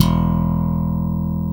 Index of /90_sSampleCDs/East Collexion - Bass S3000/Partition A/SLAP BASS-D